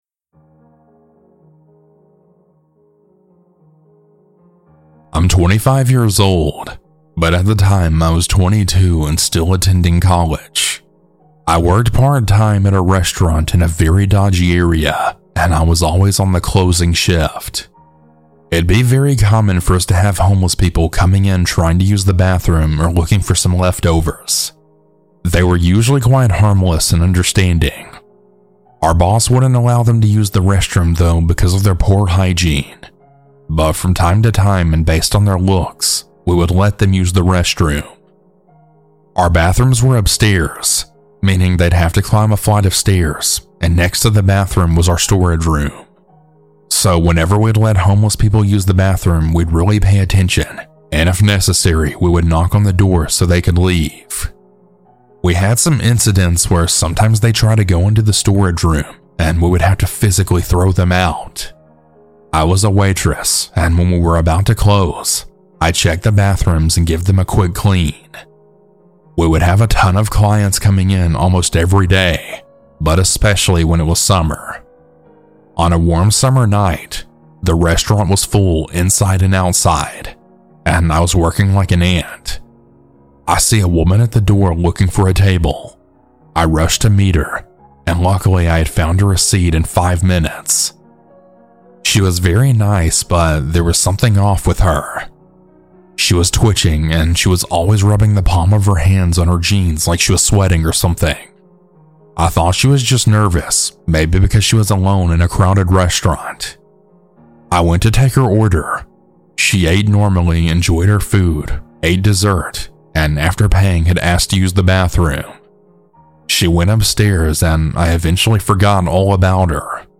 - Anonymous Huge Thanks to these talented folks for their creepy music!